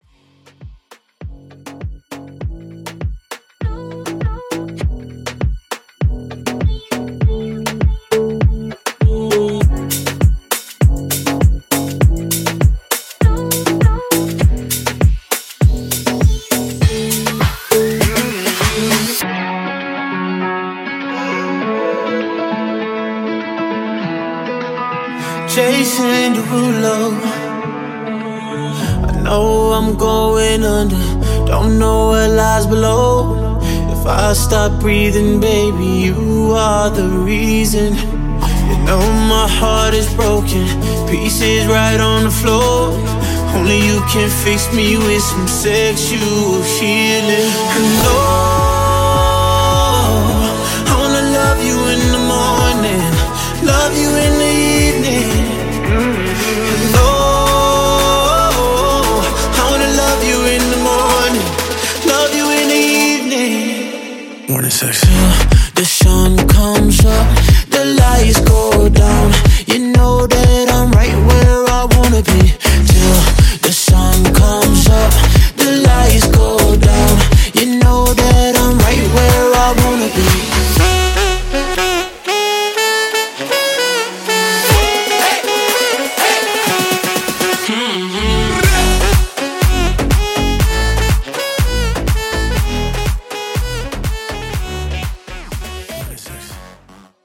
Genres: RE-DRUM , TOP40
Clean BPM: 123 Time